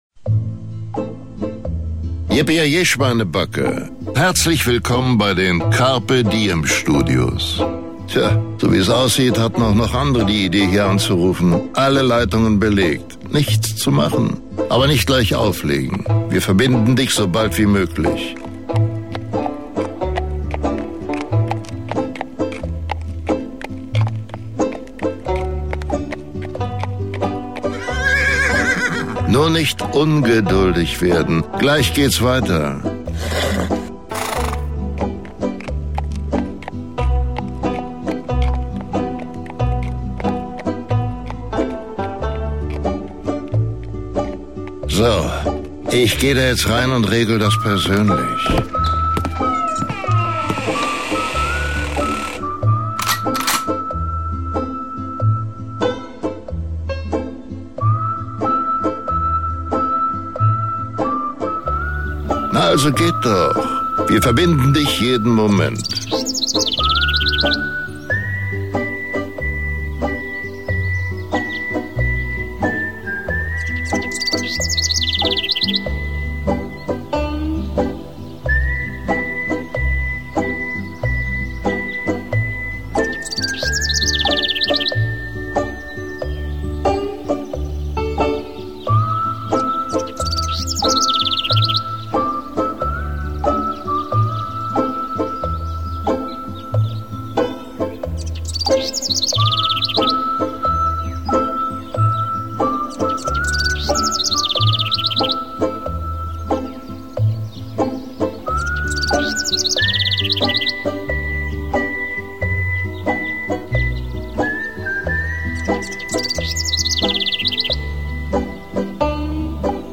Kreative Telefonwarteschleife
Warteschleife-CDS-Bruce-Willis-PASTA-Musik-Juni-2018.mp3